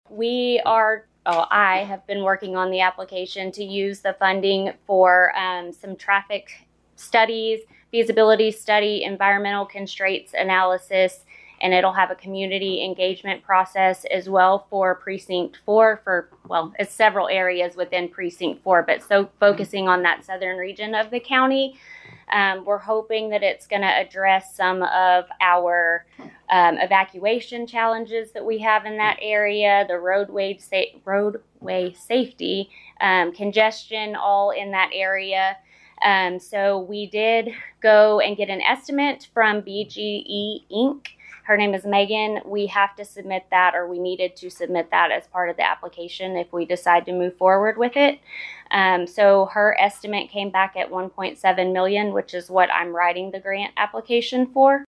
At Walker County Commissioners Court regular meeting this week, commissioners considered taking action on submitting a grant application to the Rural and Tribal Assistance Pilot Program.